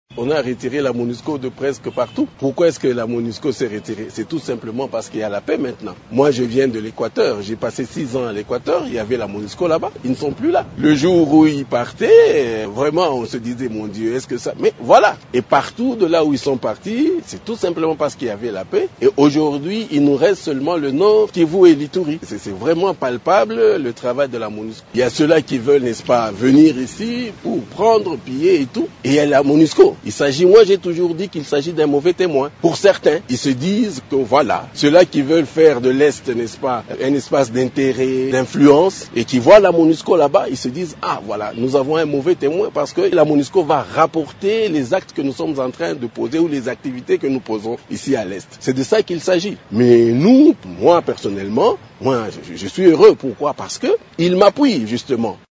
Il s’est ainsi exprimé à l’occasion des 25 ans de présence de la Mission onusienne en RDC.